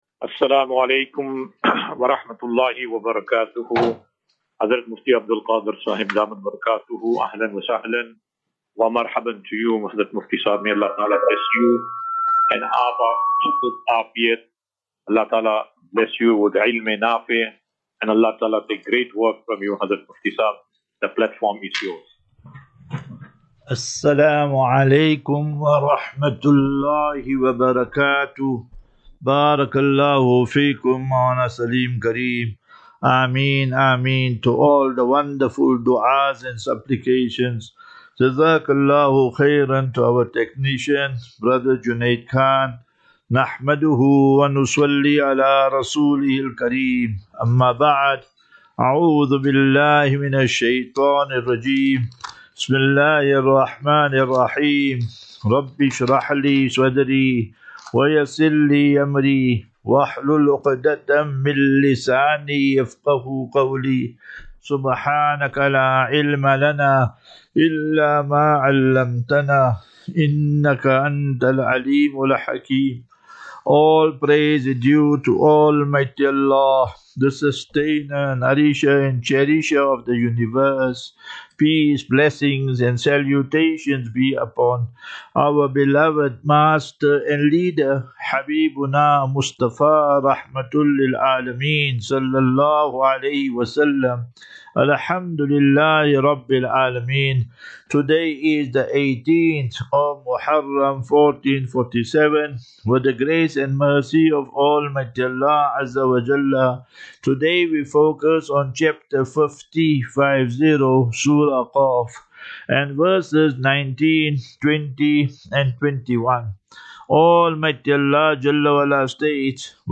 Assafinatu - Illal - Jannah. QnA.